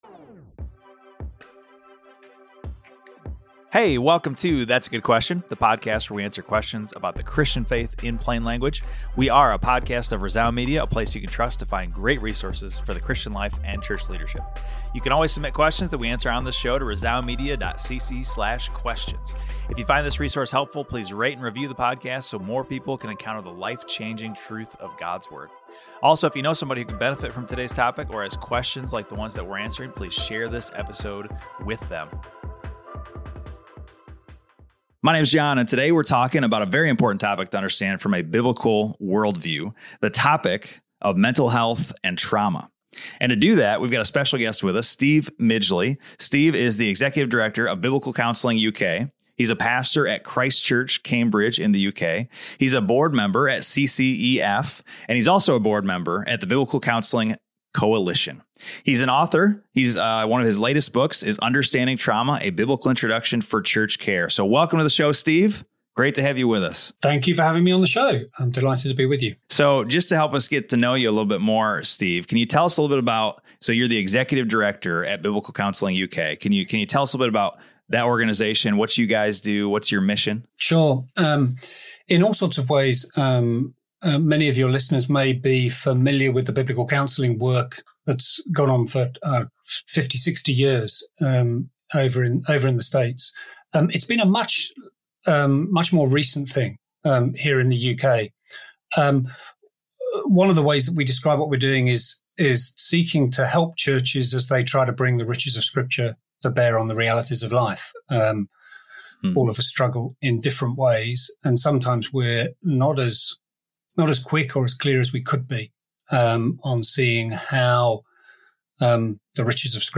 Rooted in Scripture, this conversation offers wisdom, challenges complacency, and calls us to be a people who truly reflect Jesus’ heart for the broken.